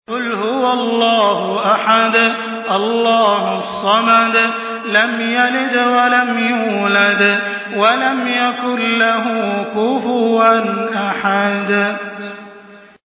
Surah Al-Ikhlas MP3 in the Voice of Makkah Taraweeh 1424 in Hafs Narration
Murattal Hafs An Asim